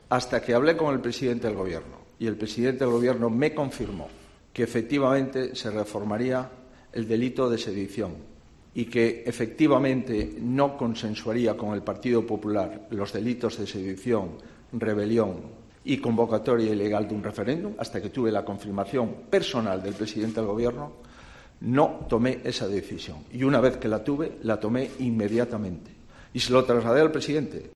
Feijóo, que ha ofrecido una conferencia en Vitoria organizada por el diario El Correo, bajo el título "Objetivo Actualidad", ha recordado que ha suspendido las negociaciones abiertas con el Gobierno la renovación del Consejo General del Poder Judicial, después de que el jefe del Ejecutivo le confirmara, en una conversación telefónica, que su intención sigue siendo reformar el delito de sedición, pese a que representantes del Gobierno le habían asegurado que este asunto no se iba a tocar.